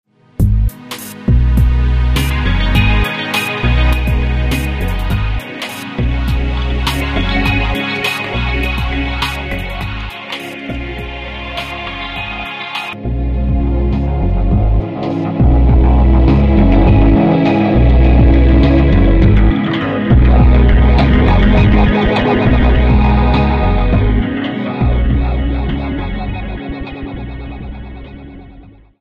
Choose a filter, add resonance and modulation, and rack up unprecedented wha-wha effects.
Each sequence is in a dry version and then effected in different ways.
Retro Filter.mp3